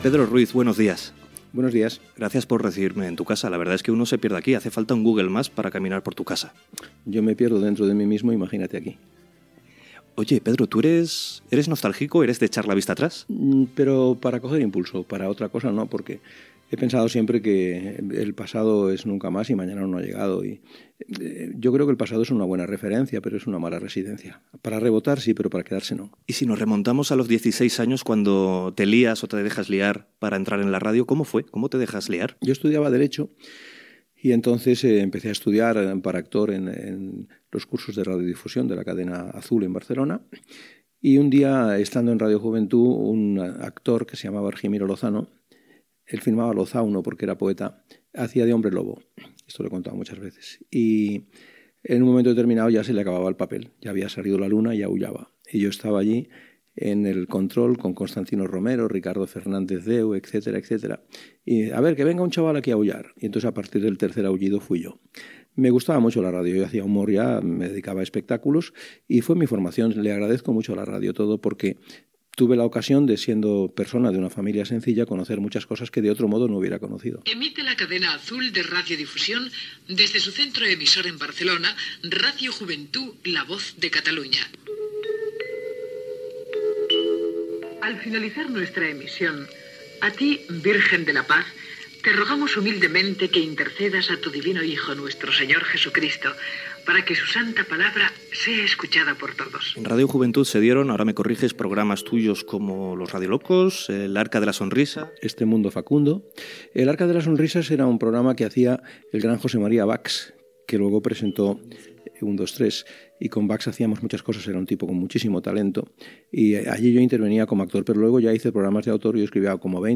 Fragment d'una entrevista a l'humorista Pedro Ruiz, sobre el seu passat radiofònic.
Entreteniment